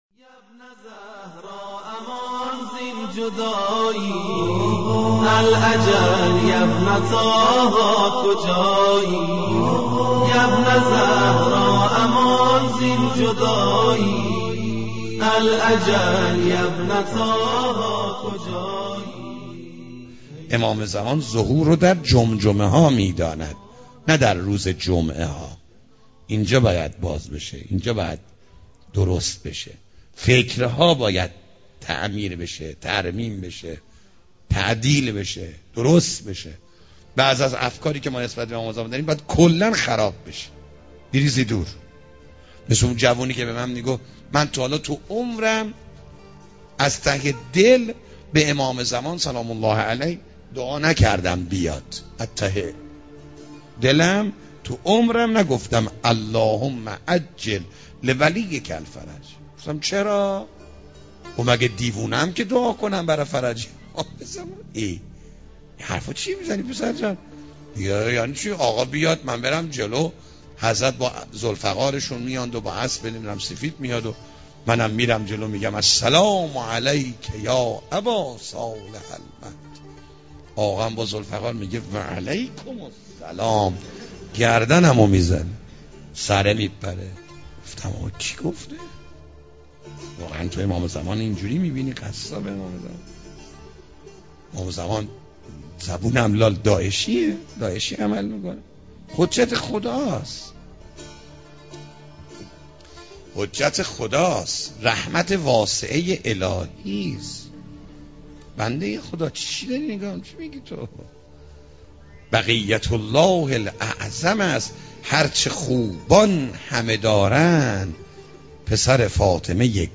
دانلود امام زمان را چگونه میبینیم سخنران : حاج آقا دانشمند حجم فایل : 4 مگابایت زمان : 13 دقیقه توضیحات : موضوعات : دسته بندی ها حاج آقا دانشمند امام زمان ع